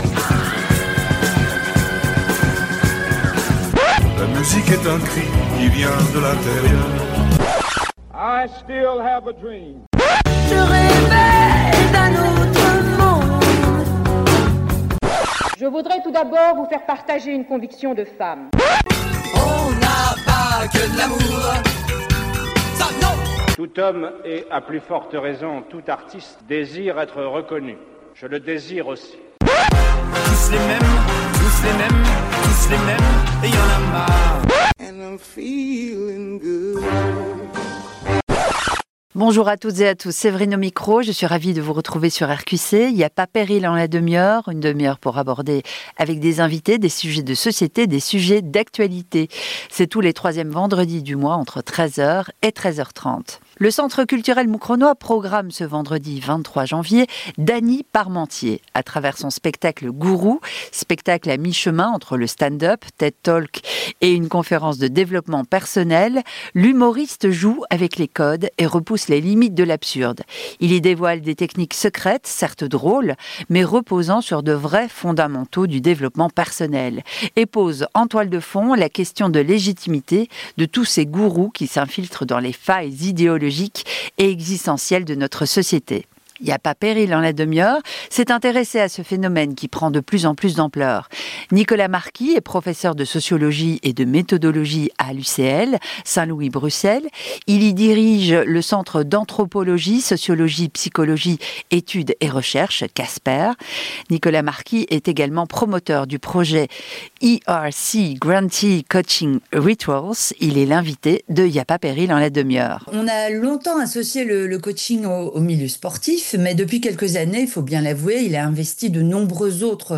Gestionnaire - Animatrice médias